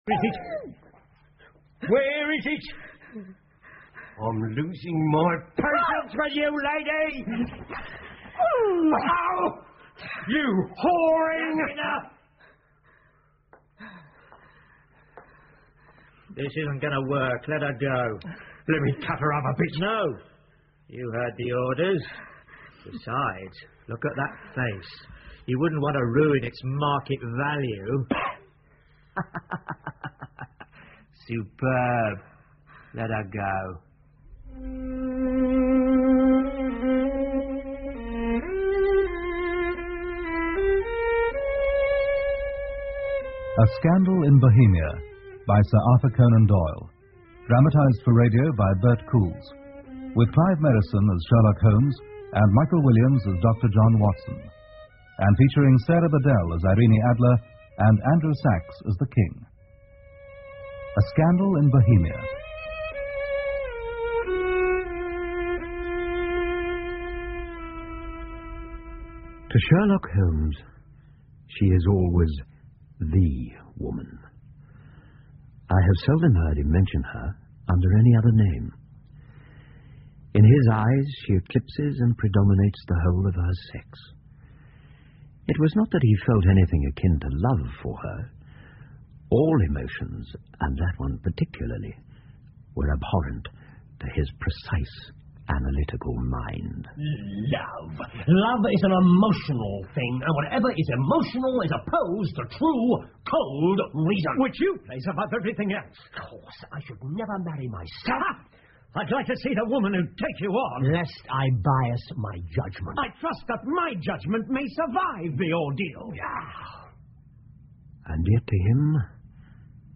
福尔摩斯广播剧 A Scandal In Bohemia 1 听力文件下载—在线英语听力室